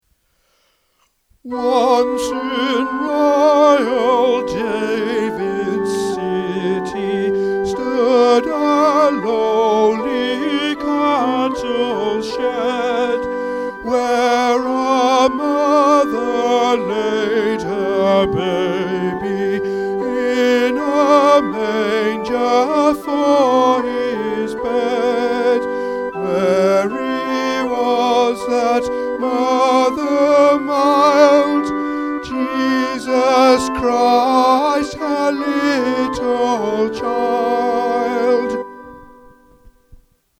Once In Royal David’s City – Tenor | Ipswich Hospital Community Choir